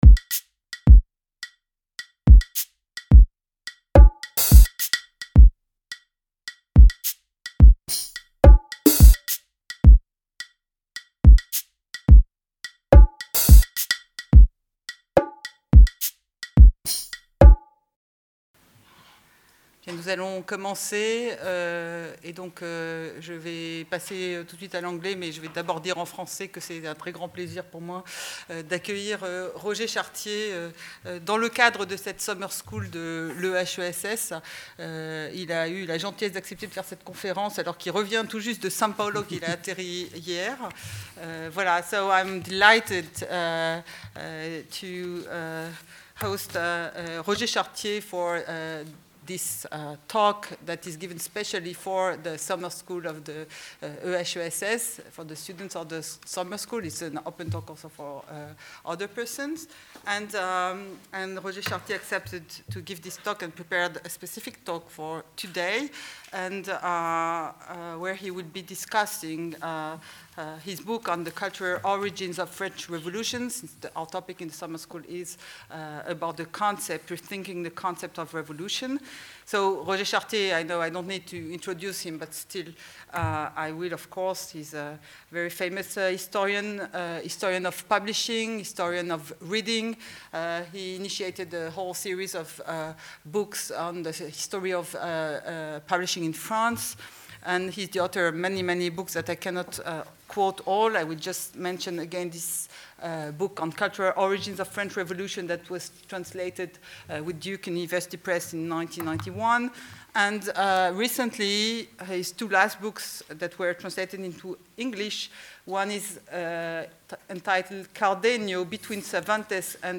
Conférence de Roger Chartier